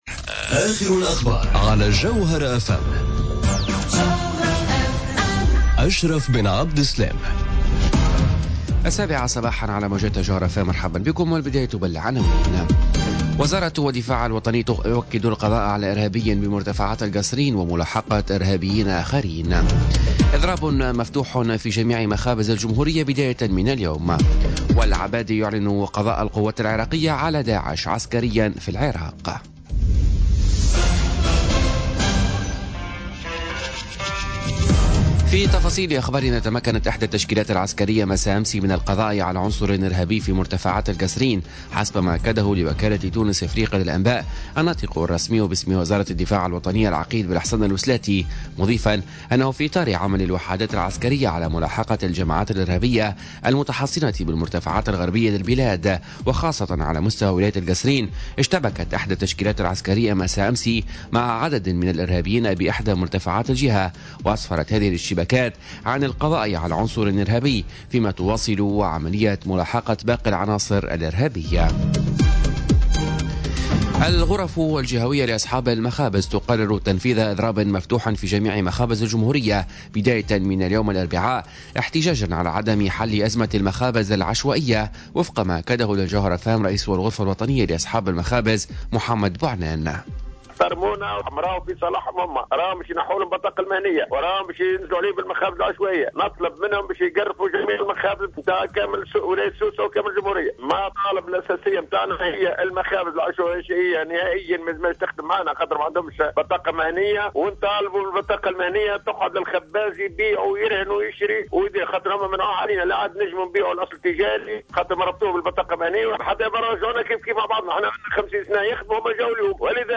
نشرة الأخبار السابعة صباحا ليوم الاربعاء 29 نوفمبر 2017